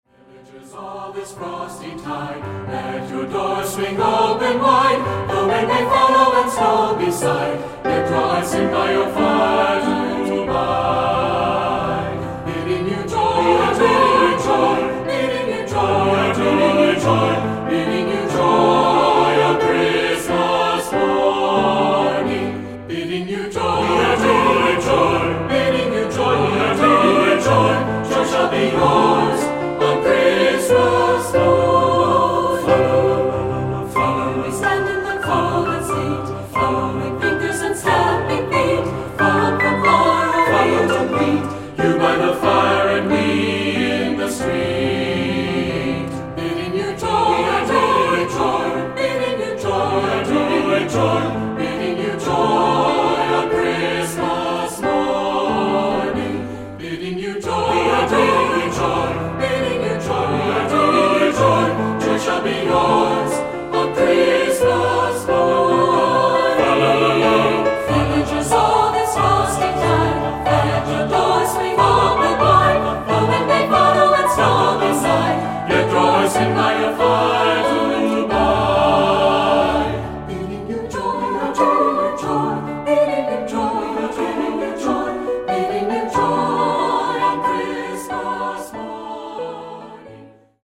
Choral Christmas/Hanukkah
Set in the style of a buoyant English dance
Opt. a cappella.